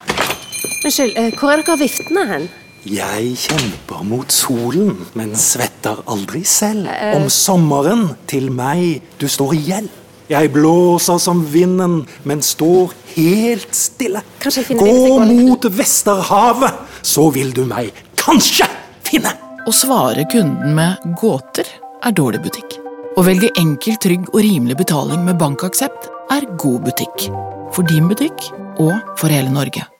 Vi liker spesielt godt den avslepne voice’en som svarer fint på dramatiseringen, fremfor en klassisk reklamevoice.